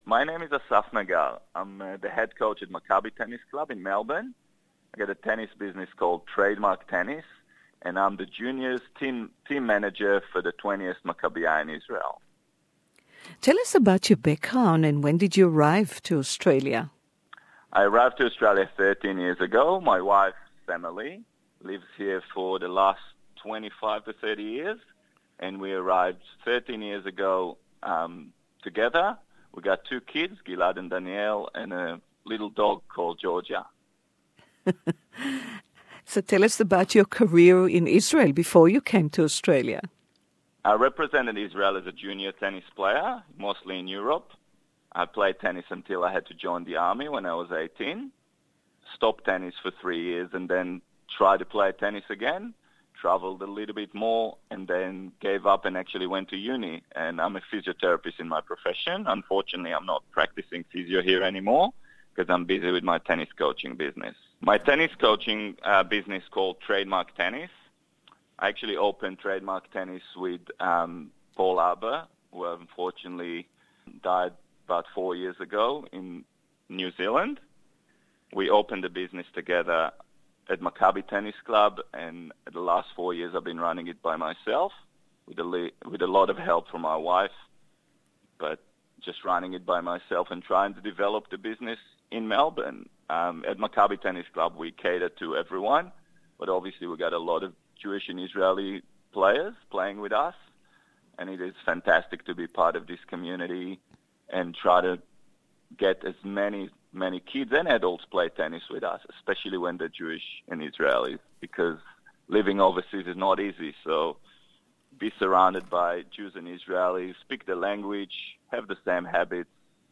English Interview